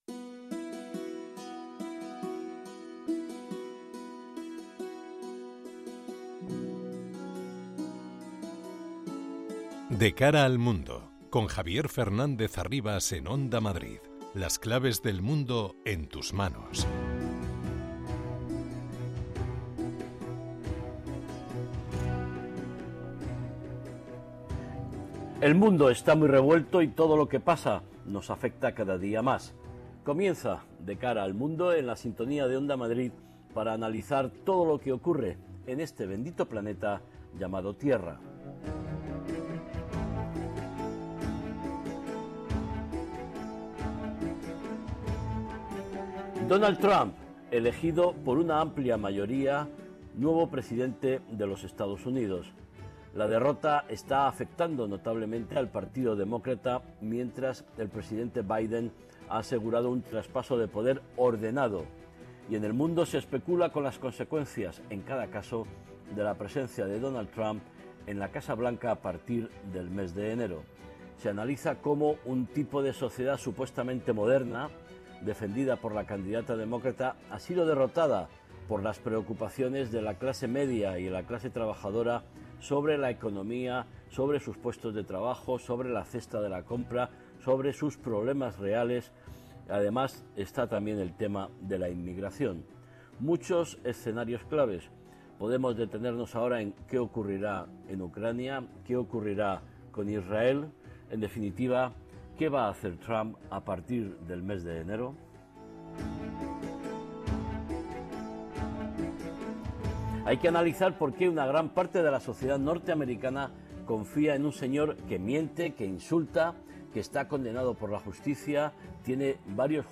analiza los principales sucesos en el panorama internacional con entrevistas a expertos y un panel completo de analistas.